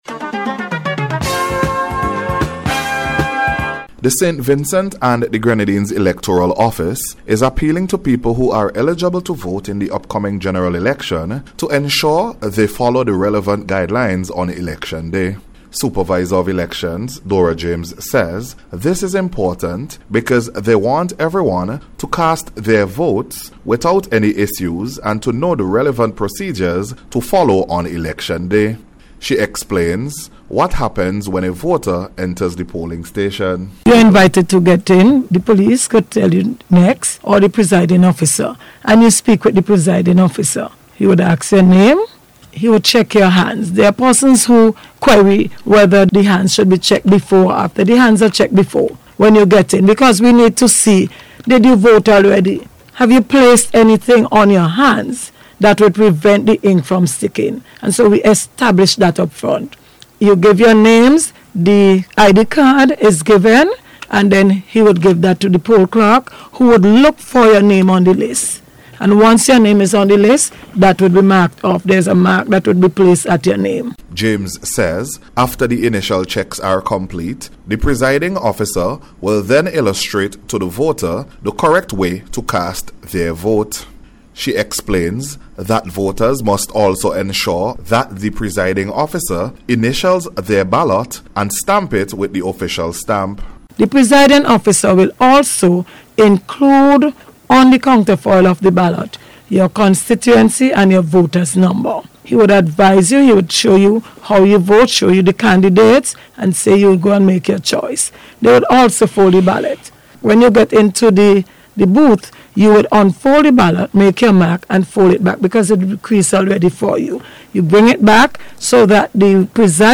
KNOW-YOUR-RIGHTS-AND-REPONSIBILITIES-ON-ELECTION-DAY-REPORT.mp3